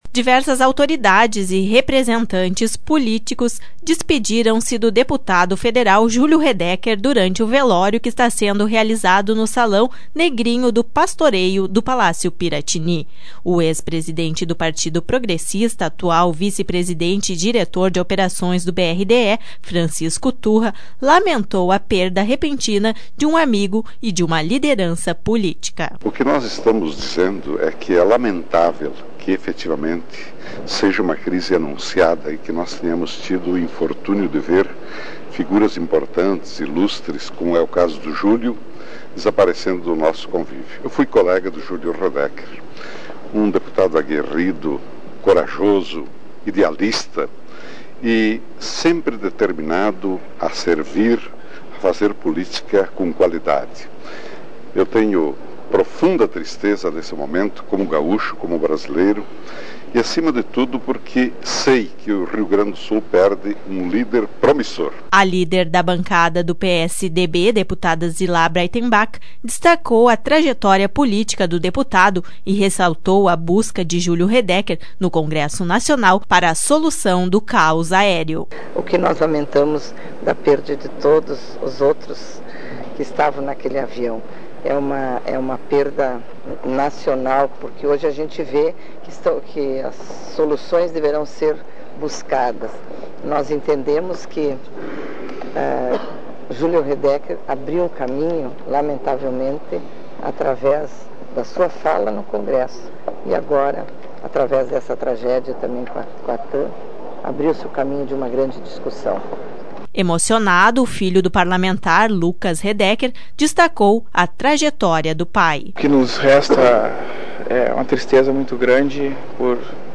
Diversas autoridades e representantes políticos foram despedir-se do deputado federal durante o velório que está sendo realizado no Salão Negrinho do Pastoreio do Palácio Piratini.O ex presidente do Partido Progressista, atual vice-presidente e diretor